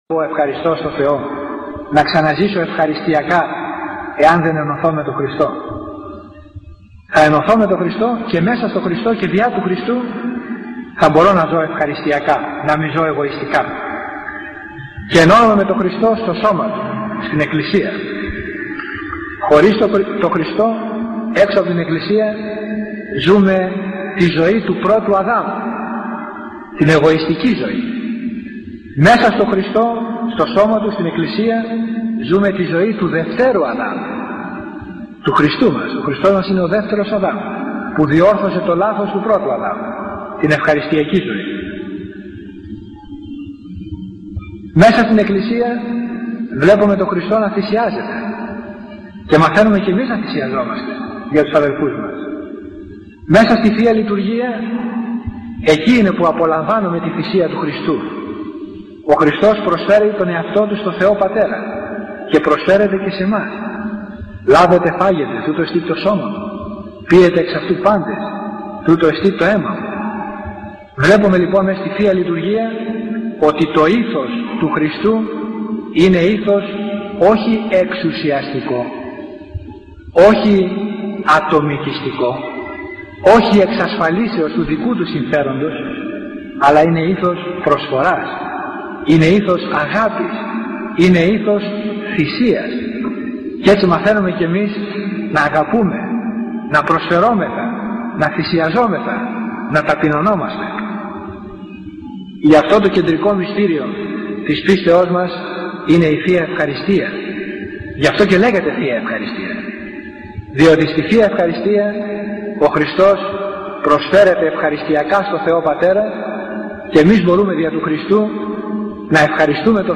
Ομιλία του γέροντα με θέμα “Η Ευχαριστιακή Ζωή”